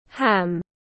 Giăm bông tiếng anh gọi là ham, phiên âm tiếng anh đọc là /hæm/
Ham /hæm/